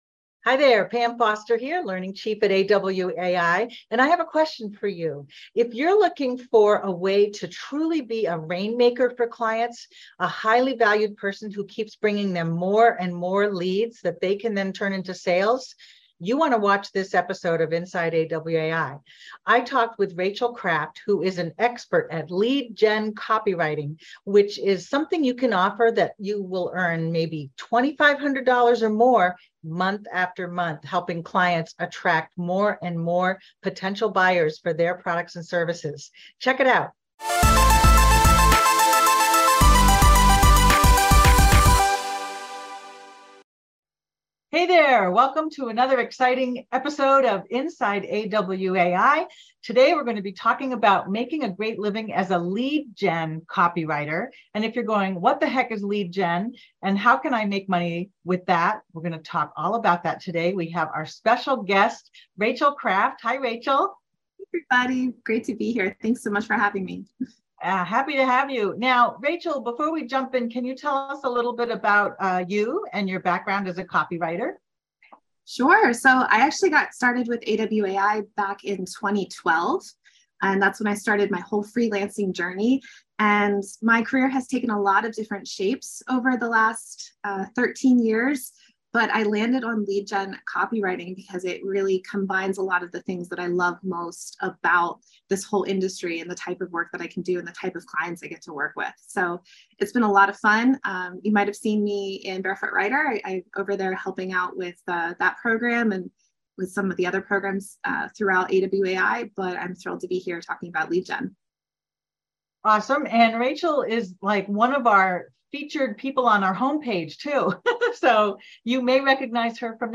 Inside AWAI Webinar and Q&A: Make A Great Living as a Lead-Gen Copywriter
As always, they opened up the conversation for a Q&A at the end.